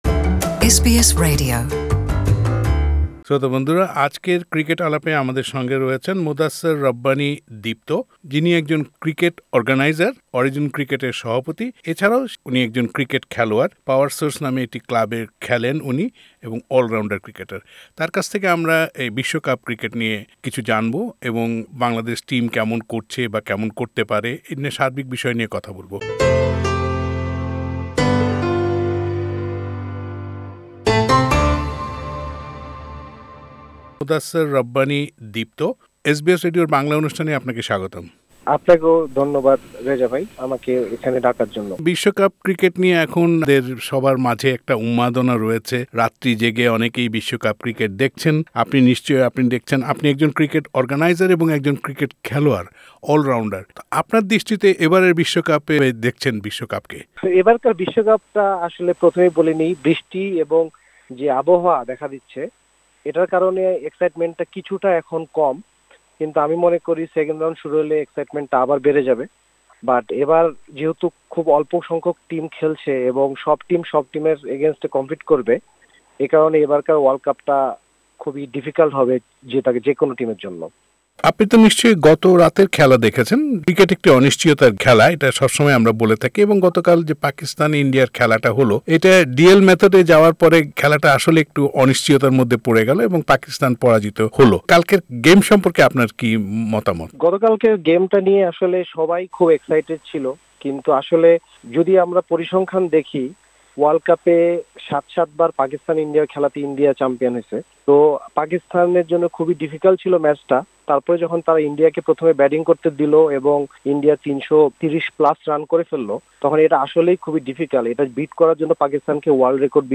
এবারের বিশ্বকাপ ক্রিকেট নিয়ে এসবিএস বাংলার সঙ্গে কথা বলেছেন তিনি।